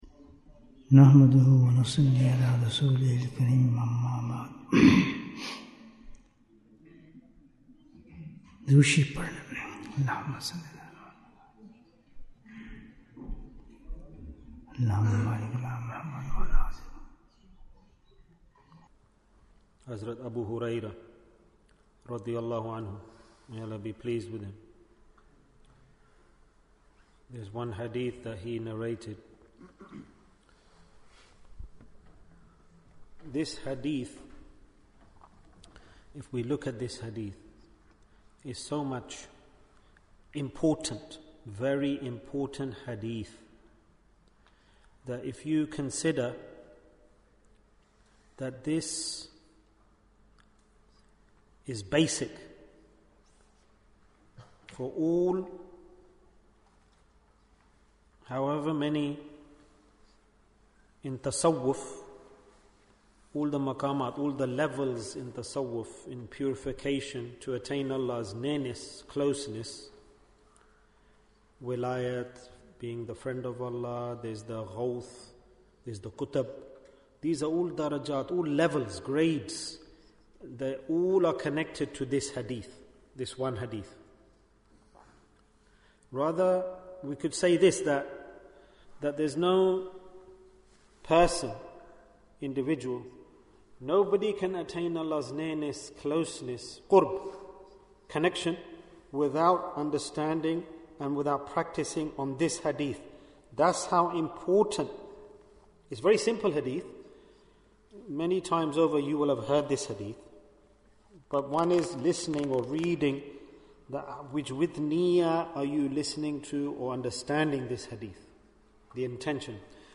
What is the Secret of Spiritual Progress? Bayan, 24 minutes13th June, 2024